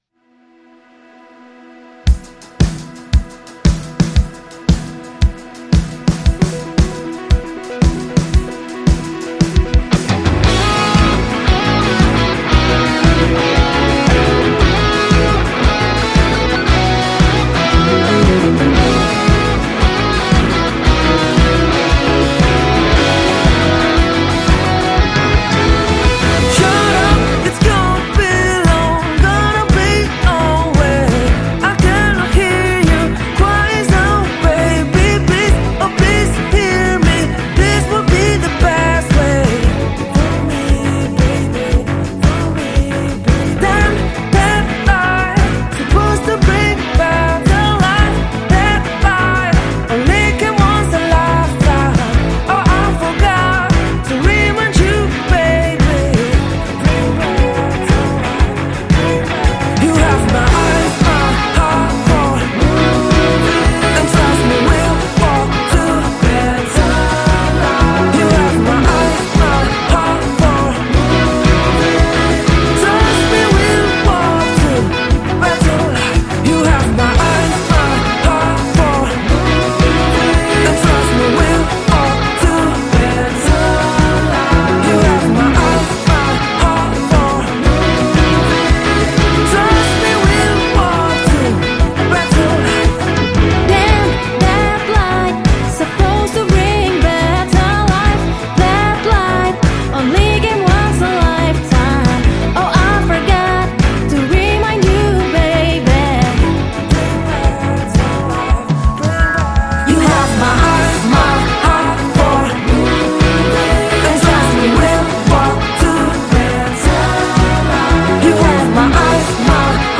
Denpasar Alternative